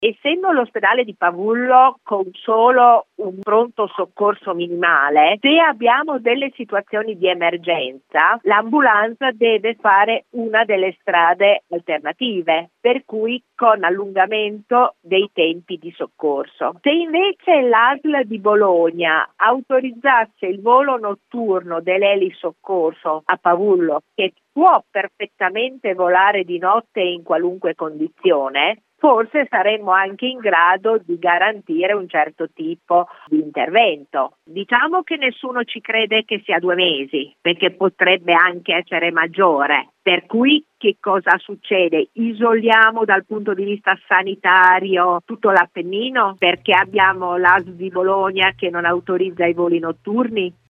Con la chiusura di almeno due mesi del ponte Rio Torto, tra Pavullo e Serramazzoni, emergono diverse problematiche: l’appennino si trova di fatto isolato dal punto di vista sanitario con difficoltà a raggiungere per le ambulanze i principali ospedali. A sottolineare il problema la deputata modenese di Fratelli D’Italia Daniela Dondi: